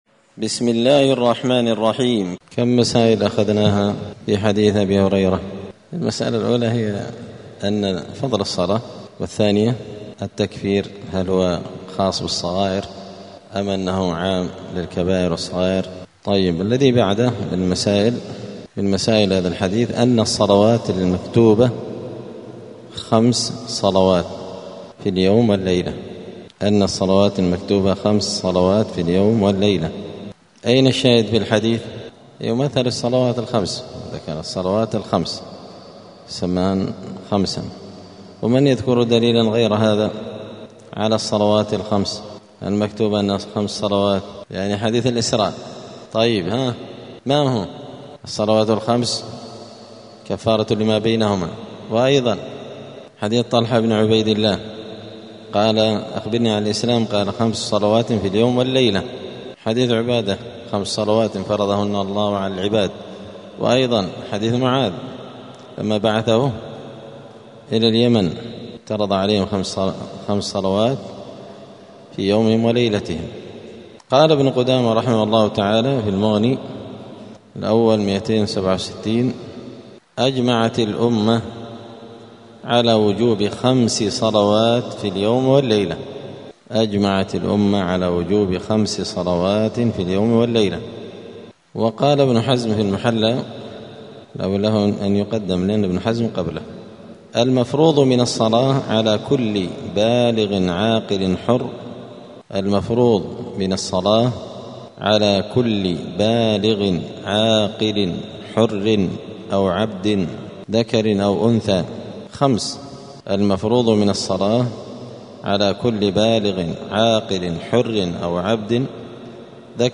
دار الحديث السلفية بمسجد الفرقان قشن المهرة اليمن
*الدرس الثالث والعشرون بعد المائة [123] {أن الصلوات المفروضة خمس في اليوم والليلة}*